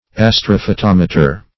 Search Result for " astrophotometer" : The Collaborative International Dictionary of English v.0.48: Astrophotometer \As`tro*pho*tom"e*ter\, n. [Pref. astro- + photometer.]